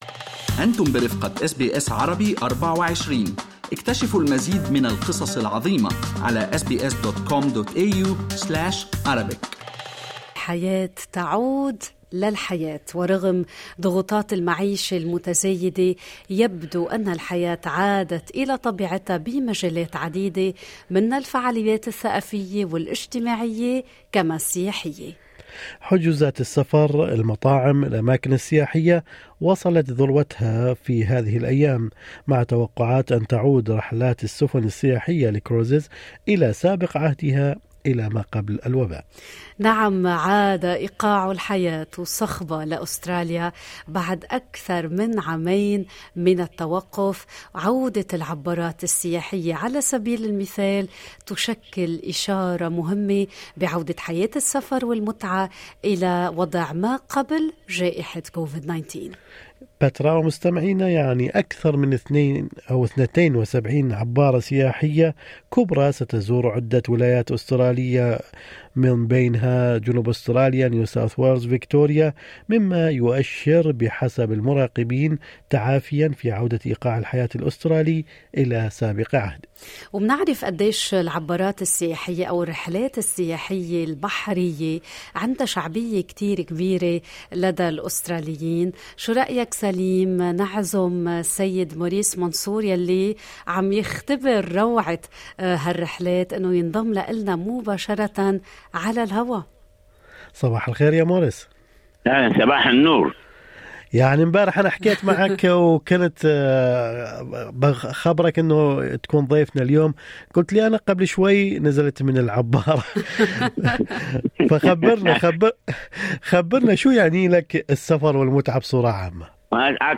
يمكنكم الاستماع الى التسجيل الصوتي الكامل للمقابلة بالضغط على التسجيل أعلاه.